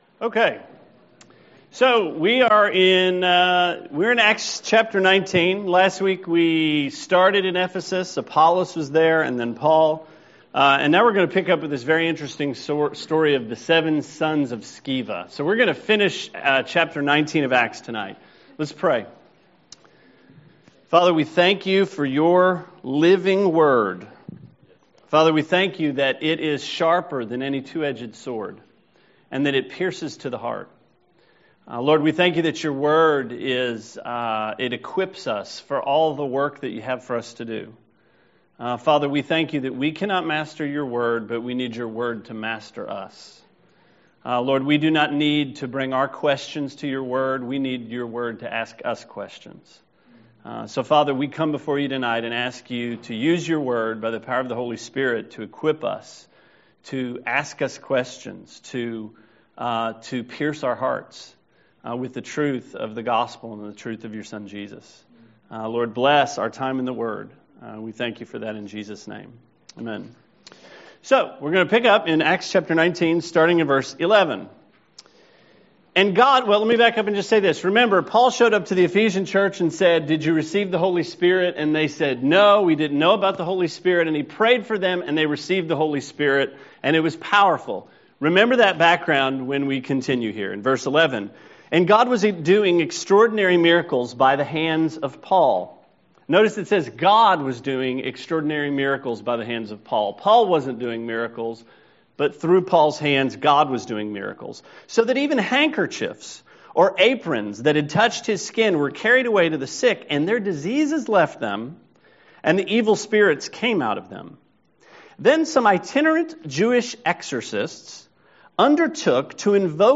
Sermon 8/12: Acts 19 – Trinity Christian Fellowship